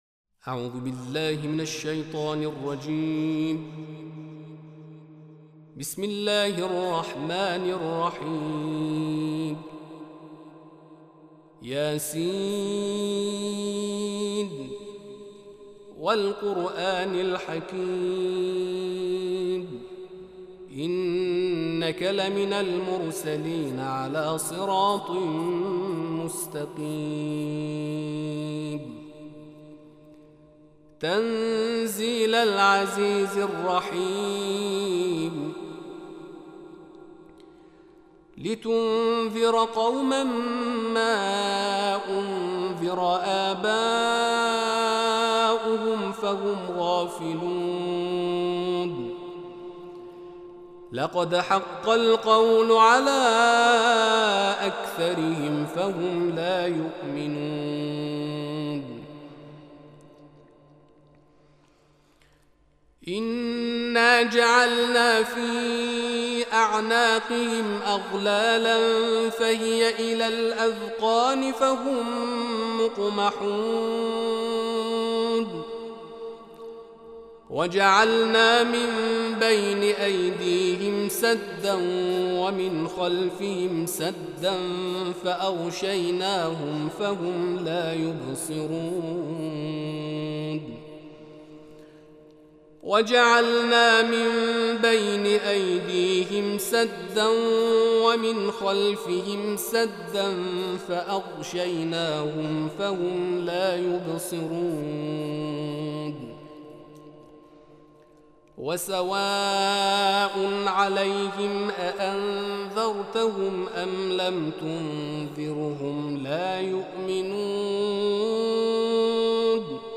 Tartil-Yasin.mp3